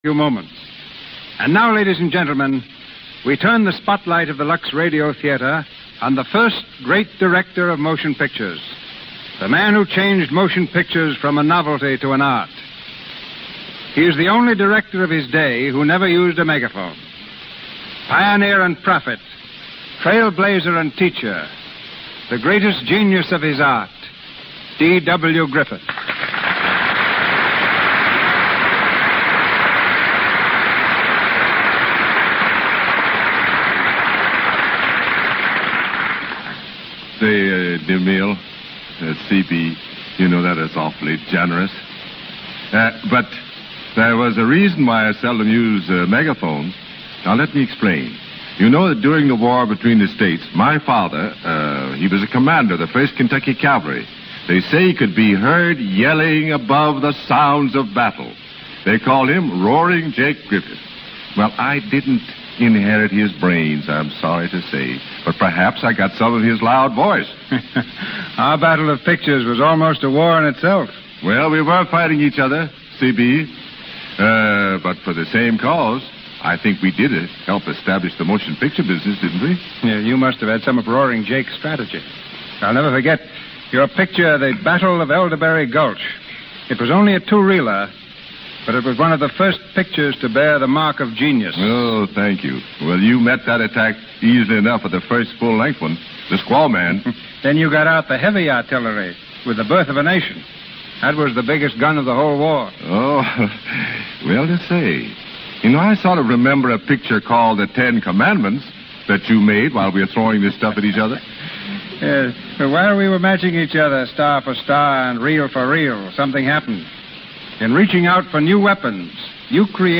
Let’s join D.W. on June 29, 1936 as Cecil B. DeMille welcomes him: